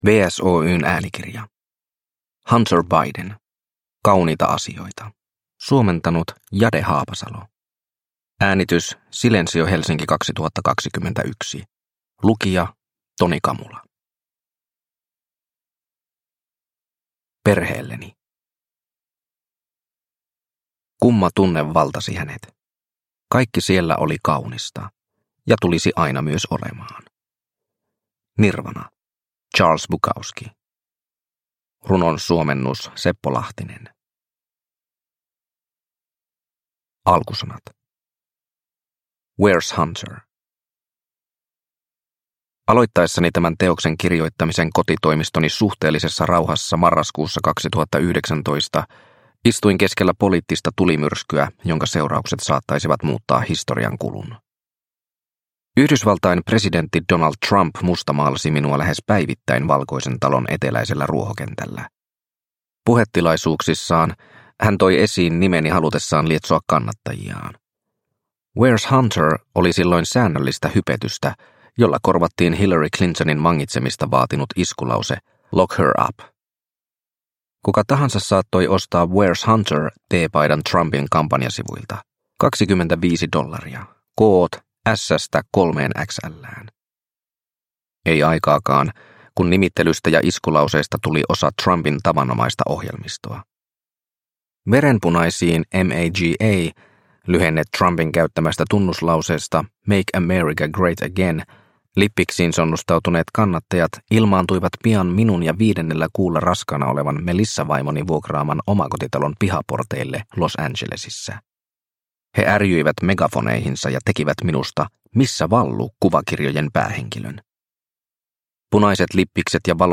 Kauniita asioita – Ljudbok – Laddas ner